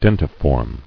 [den·ti·form]